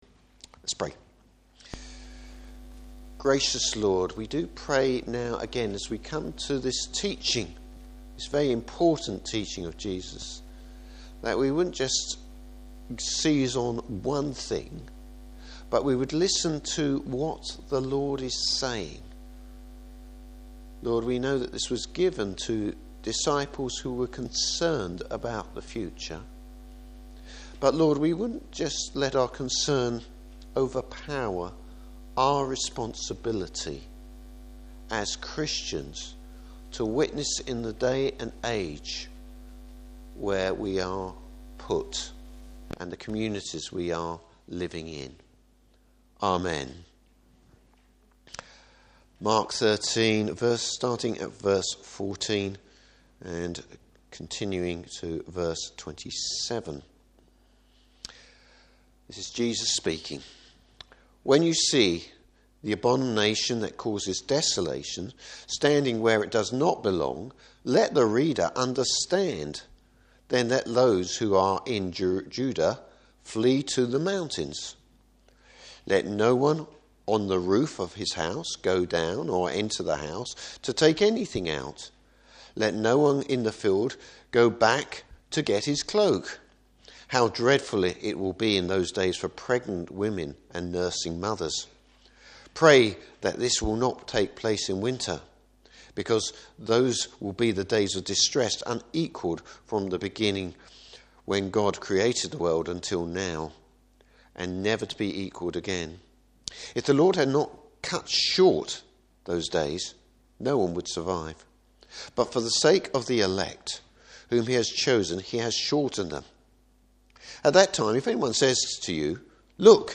Service Type: Morning Service The destruction of the Temple and the coming of Christ.